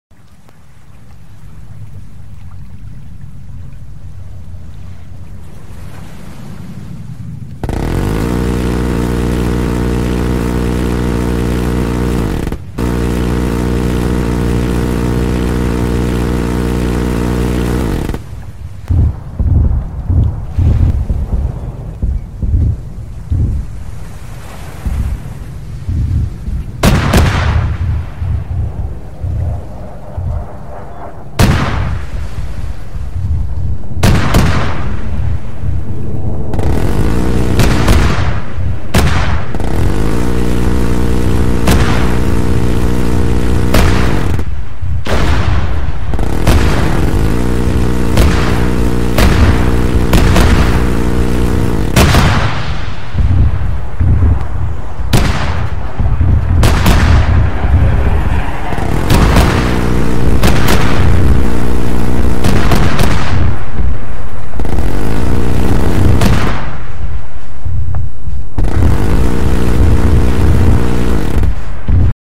C RAM Air Defense System In Sound Effects Free Download